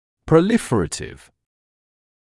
[prə’lɪfərətɪv][прэ’лифэрэтив]пролиферативный, разросшийся (о клетках)